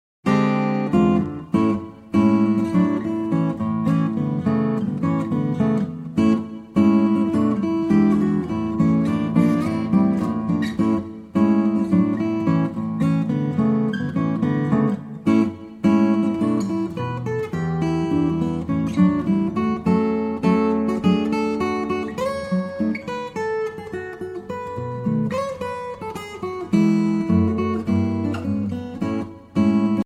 Arranger: Fingerstyle Guitar